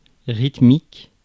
Ääntäminen
Ääntäminen France: IPA: [ʁit.mik] Haettu sana löytyi näillä lähdekielillä: ranska Käännös Ääninäyte Adjektiivit 1. rhythmic US 2. rhythmical Suku: f .